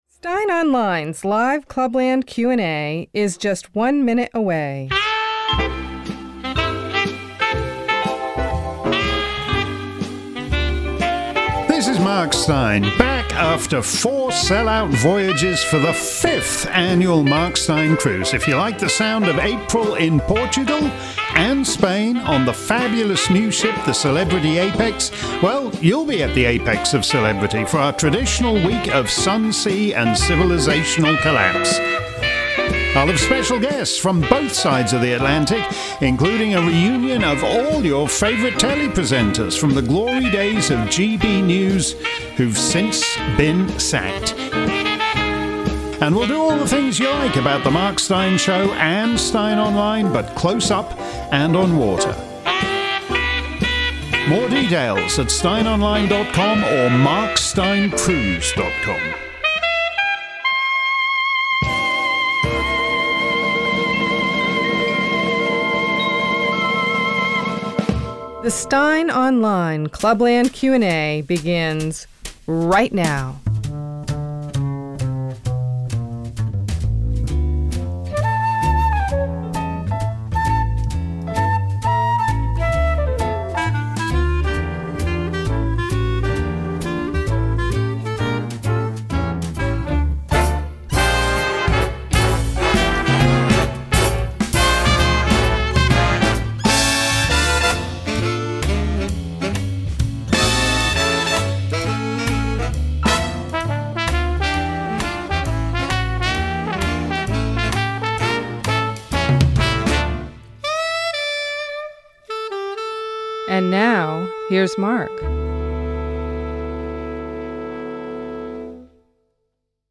If you missed today's edition of Steyn's Clubland Q&A live around the planet, here's the action replay. This week's show covered a range of territory from Trump's designs on Greenland to the new Canadian PM as The Godfather via Farage as cultural conservative.
Notwithstanding a few vocal problems from Mark, we hope you'll find this week's show worth an hour-plus of your time.